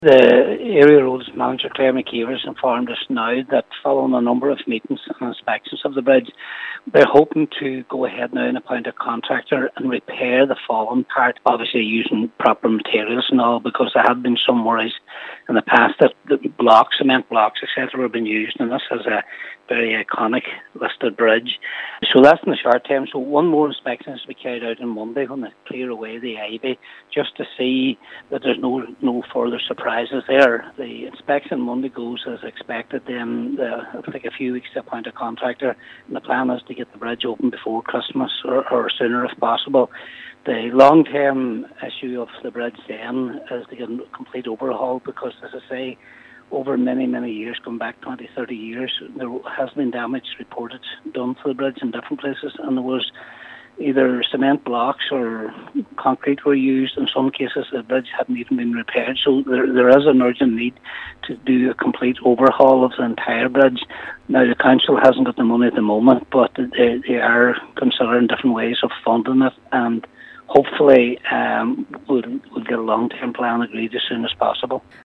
However, Cathaoirleach of the Stranorlar Municipal District Councillor Patrick McGowan says a long term solution is vital: